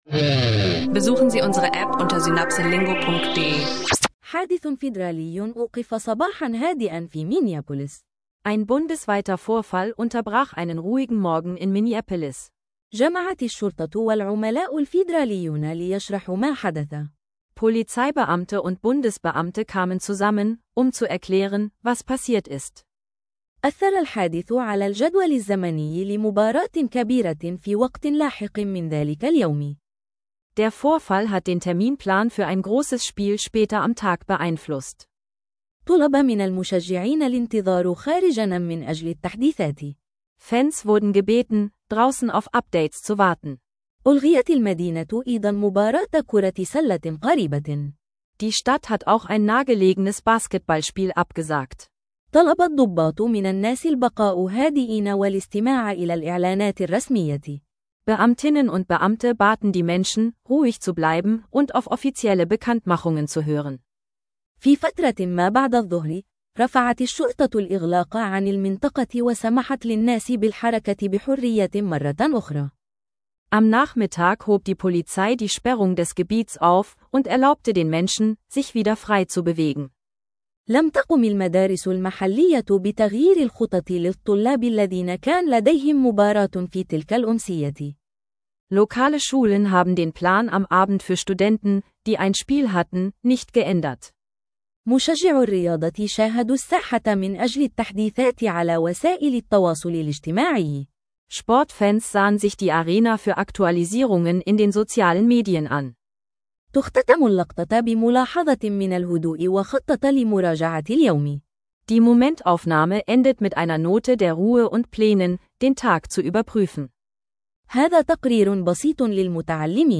Ein leichter Lesebericht über einen bundesweiten Vorfall in Minneapolis kombiniert mit einem Anfänger- bis Fortgeschrittenen-Sportdialog.